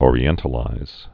(ôrē-ĕntl-īz)